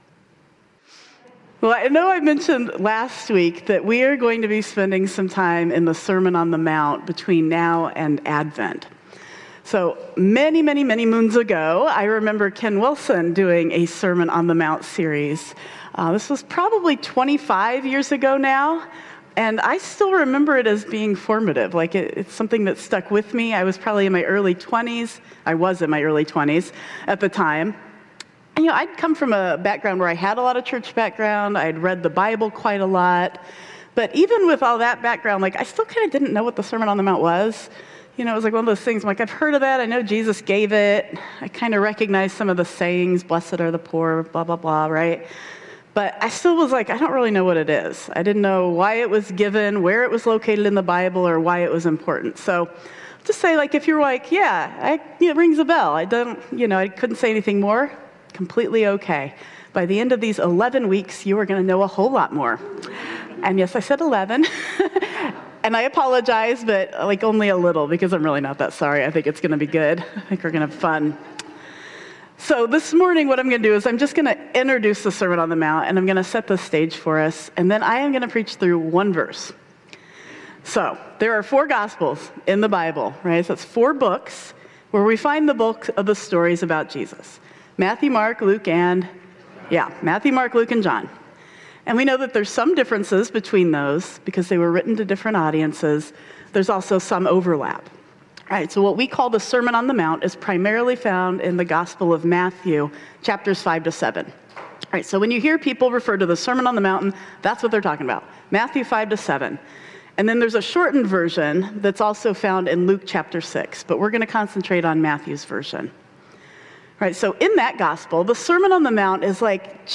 14 Sep 2025 | Sermon On the Mount: Let Us Begin - Blue Ocean Church Ann Arbor